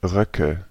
Ääntäminen
Ääntäminen Tuntematon aksentti: IPA: /ˈʀœkə/ Haettu sana löytyi näillä lähdekielillä: saksa Käännöksiä ei löytynyt valitulle kohdekielelle. Röcke on sanan Rock monikko.